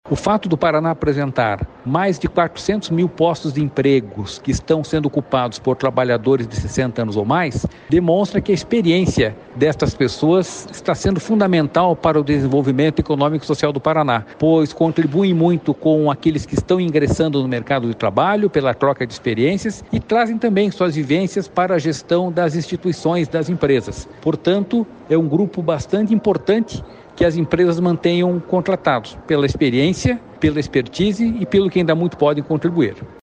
Sonora do diretor-presidente do Ipardes, Jorge Callado, sobre a marca de 400 mil trabalhadores ocupados com 60 anos de idade ou mais no segundo trimestre de 2023 no Paraná